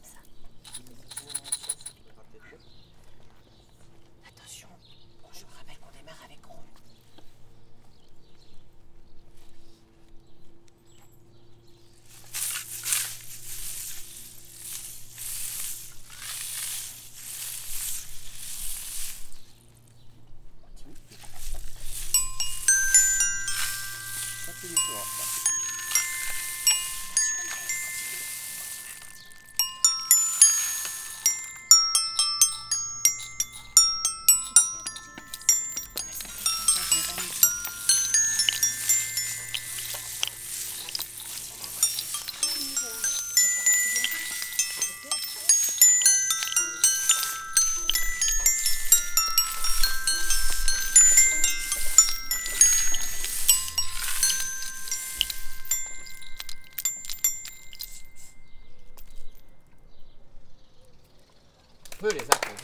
-les bâtons de pluie
-les crécelles
-les élastophones
-les xylophone
-les maracas
Ensuite nous avons expérimenté, manipulé, produits des sons en essayant chaque instrument.
Pour finir, chaque groupe a joué un petit concert avec les instruments.